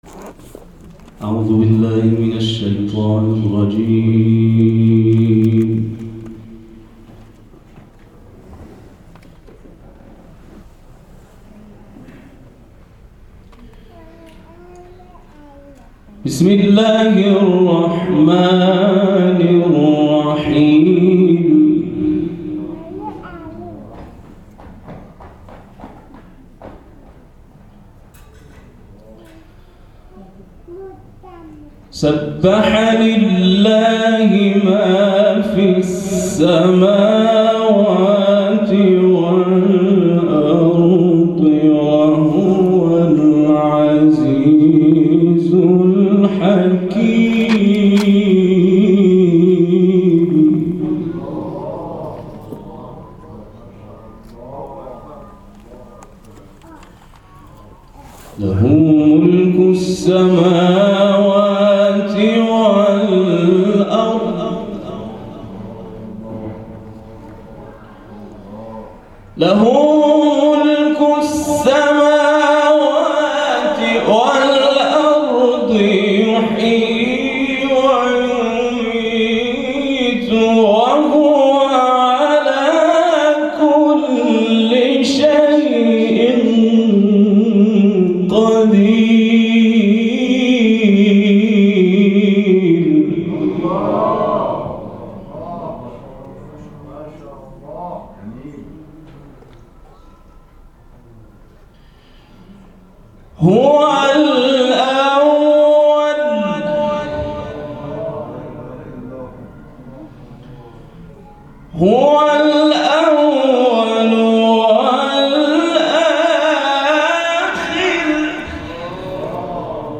این تلاوت کوتاه 19 اسفندماه در پایان سومین همایش بنیاد خانه قرآن در فرهنگ‌سرای قرآن اجرا شده است.